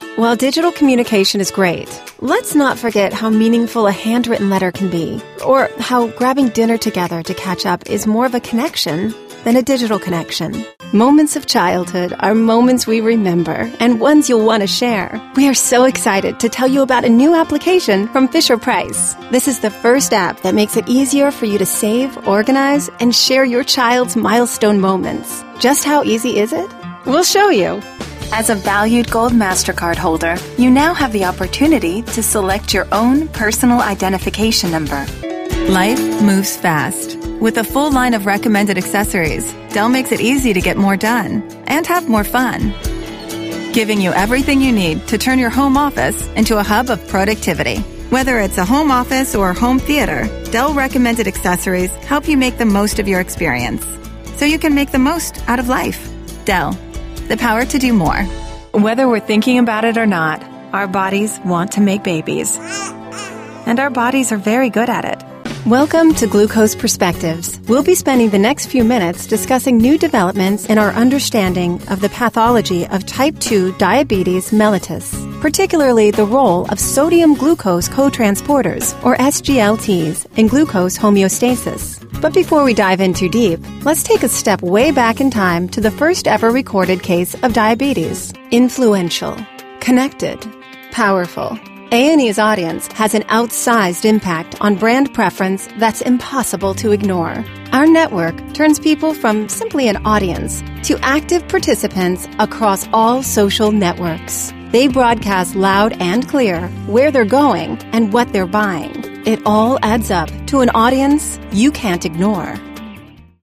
English (American)
Narration
Young Adult
Middle-Aged
ConversationalWarmEngagingFriendlySassySmart